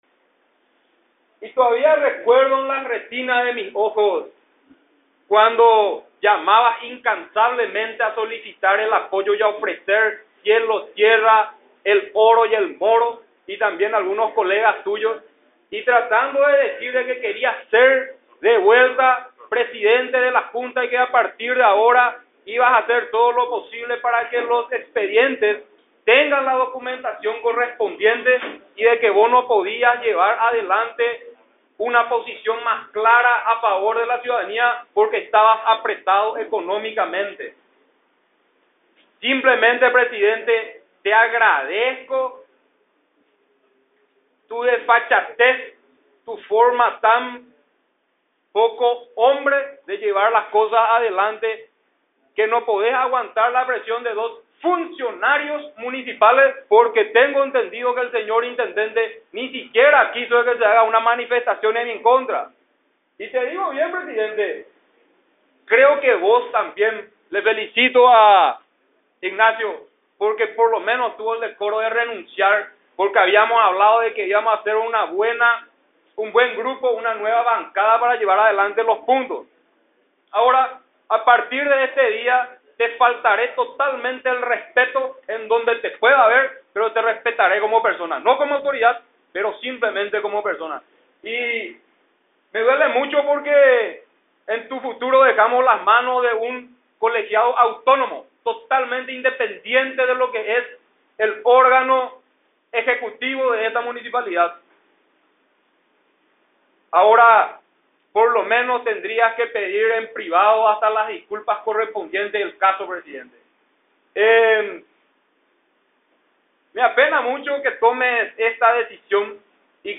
[box type=»shadow»] Hoy en sesión ordinaria de la Junta Municipal, los concejales colorados lograron unirse para nombrar al edil Alcibíades Quiñones (ANR) como presidente de la Comisión Asesora de Hacienda y Presupuesto en vez de Freddy Franco (PLRA), que fue elegido hace tan solo ocho días atrás.
[/box](AUDIO DE LAS EXPRESIONES DEL EDIL FREDDY FRANCO)